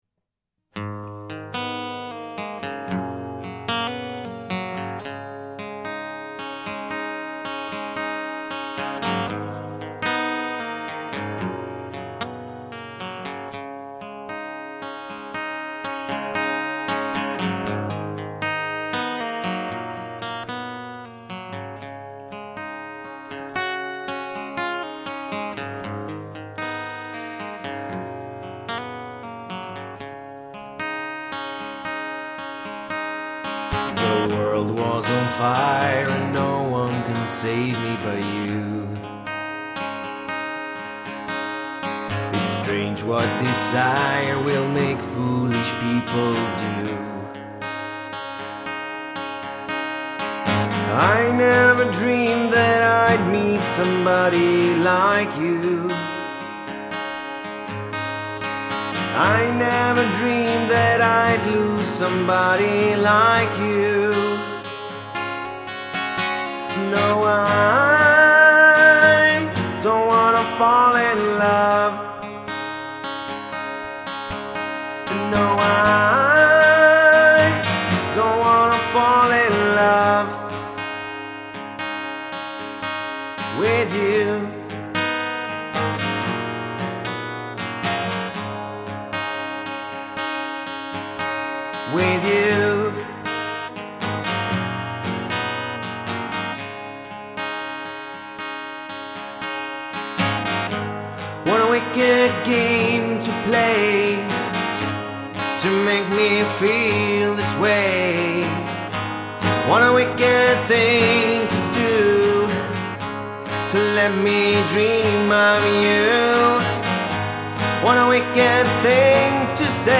• Solomusiker
• Sångare/Sångerska
• Trubadur